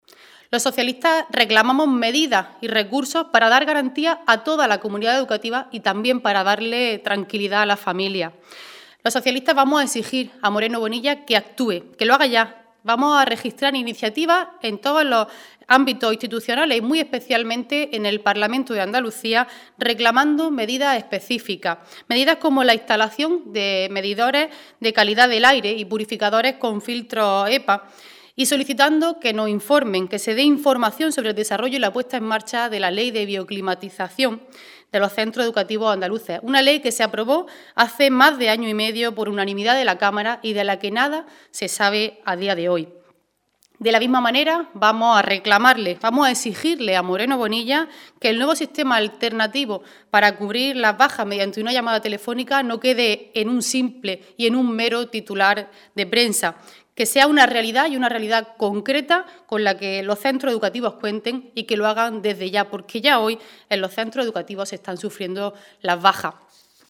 Noemí Cruz en rueda de prensa